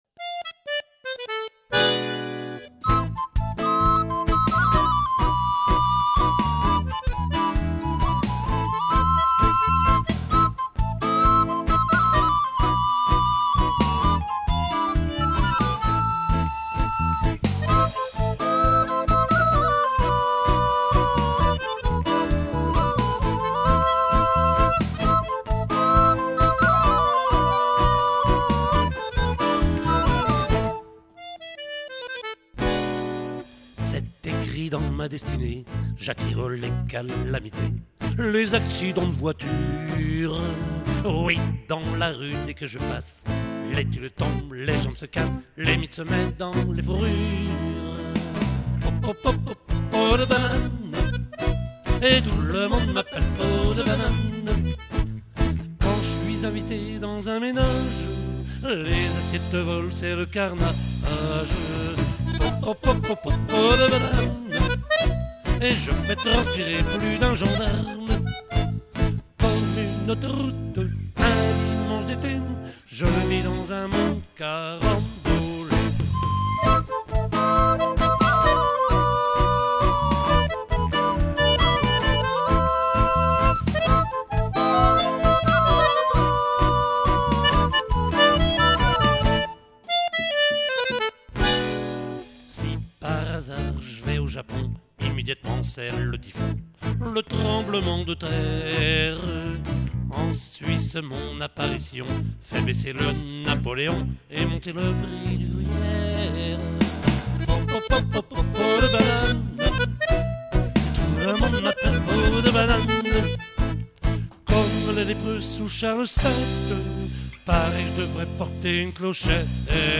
Maquette réalisée en Novembre 2006
aux studios du Micro-Bleu - RUCA (Côtes du Nord)
batterie
chant, guitare acoustique, batteur de goule
guitare basse
whistles
accordéon diatonique